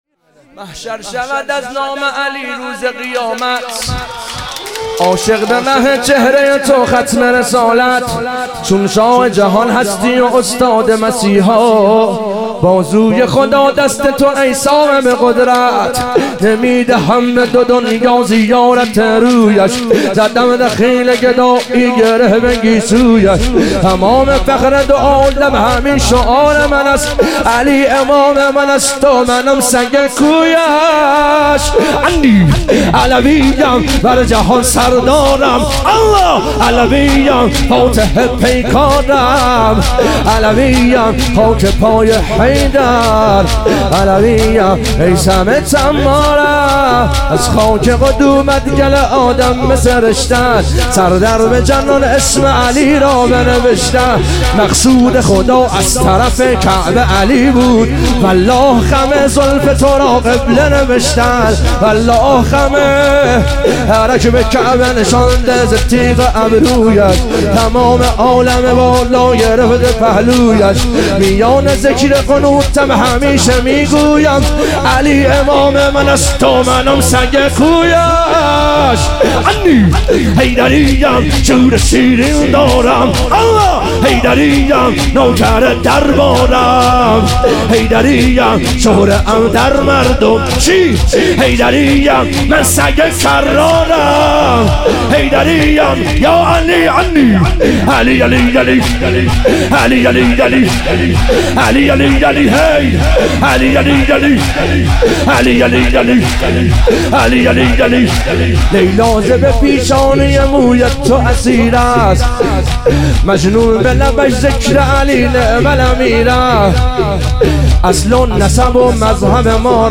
ظهور وجود مقدس حضرت زینب علیها سلام - شور